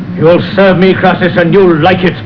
orders around another unwilling slave, who is